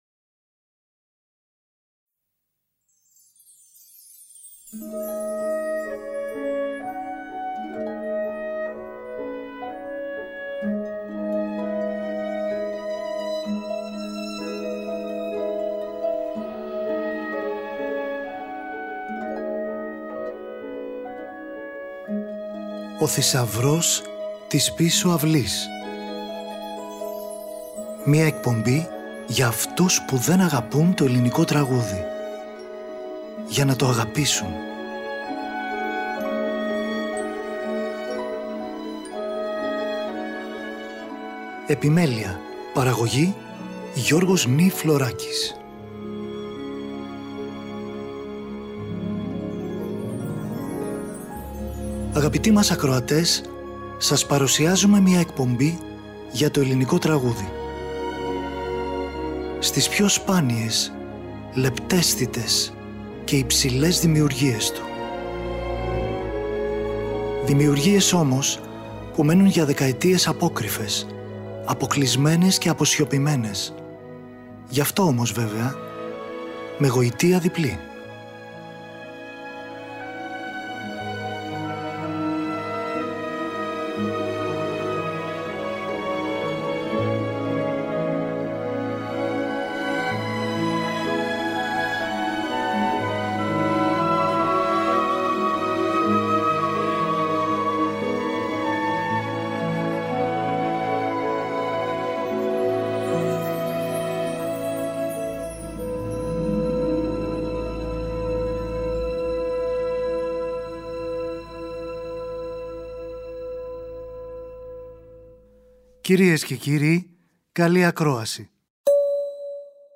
αποσπάσματα από σουίτες μπαλέτου